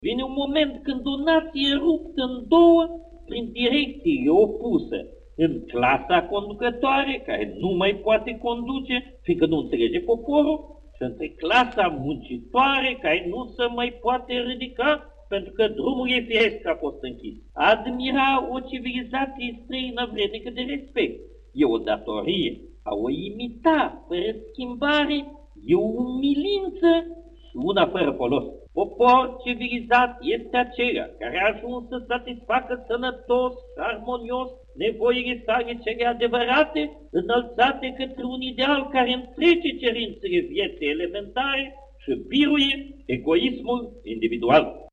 Printre acestea, Nicolae Iorga – savant, enciclopedist şi conferenţiar la Radioul public, în perioada interbelică.